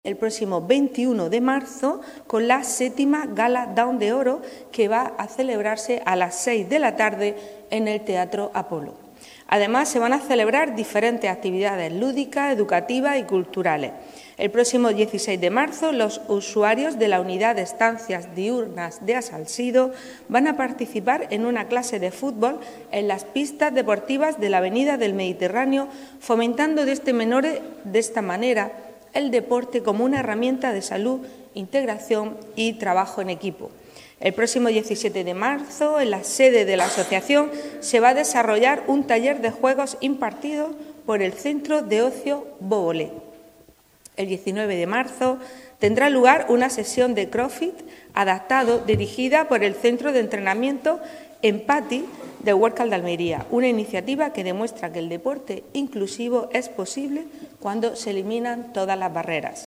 PAOLA-LAYNEZ-CONCEJALA-FAMILIA-ACTIVIDADES-DIA-SINDROME-DOWN.mp3